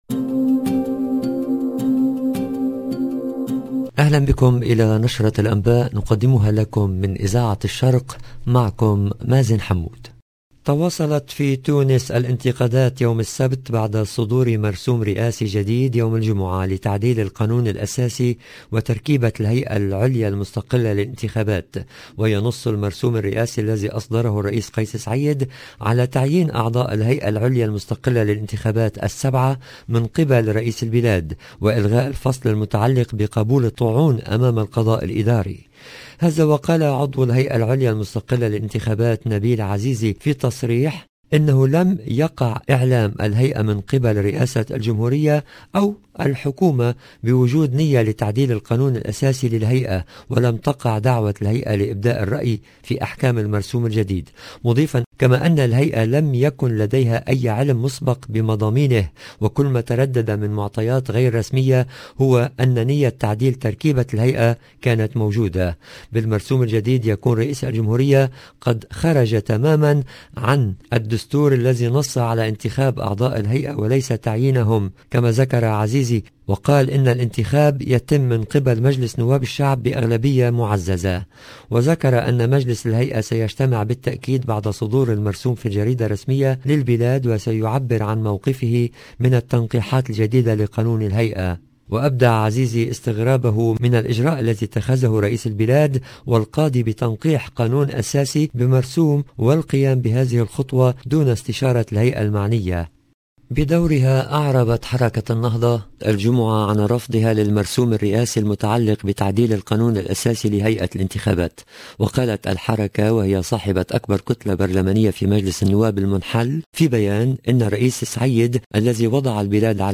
EDITION DU JOURNAL DU SOIR EN LANGUE ARABE DU 23/4/2022